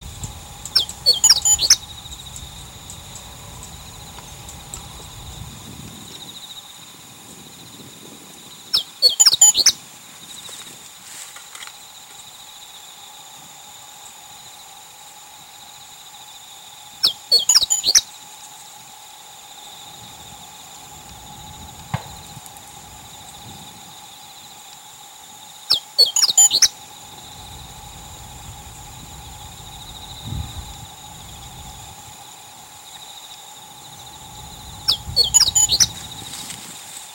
鳴 き 声：地鳴きはチッ、チッと鳴く。ちょっとホオジロの囀りに似た声でピーッピーッツーピーとかピーッピーッツクチュピー等と囀る。
鳴き声２